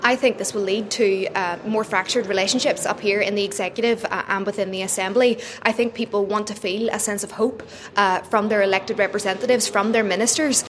East Derry MLA Cara Hunter fears relations will become more fractured…………